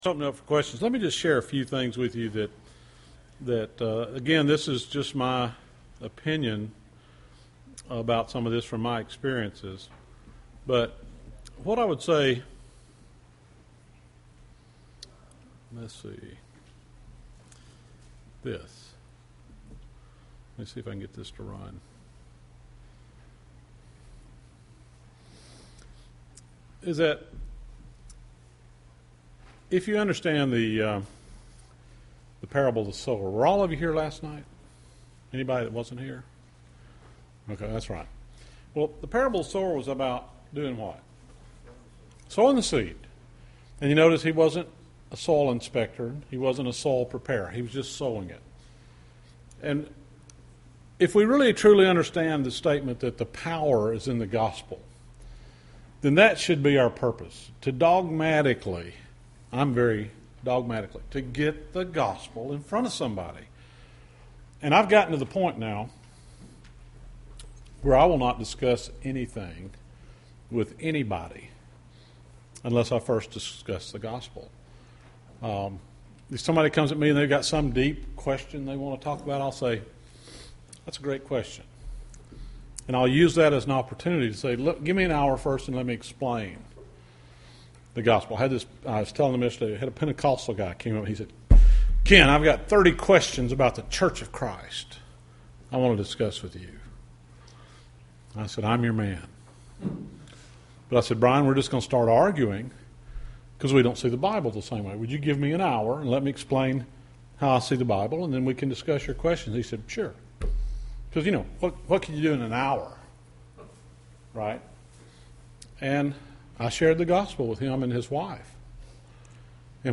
The Big Picture of the Bible Q&A Session
Sermons